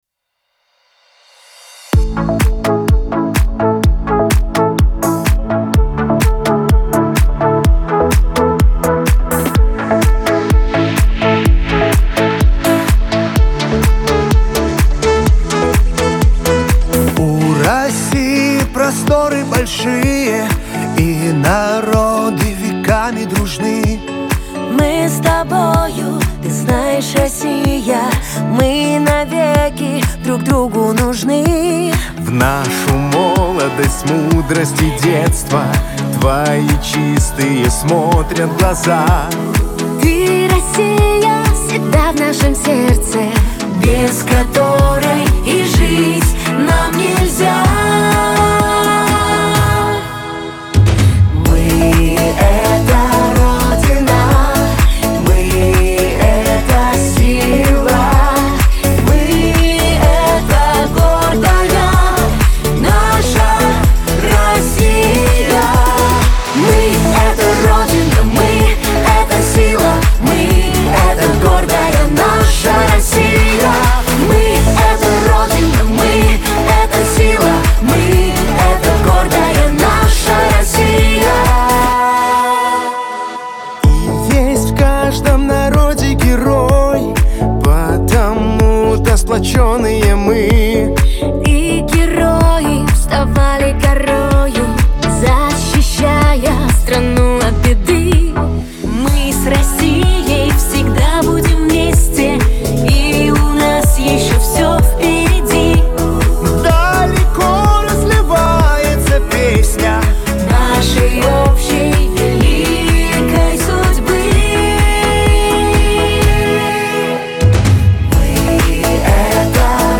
• Качество: Хорошее
• Жанр: Детские песни
Детская песня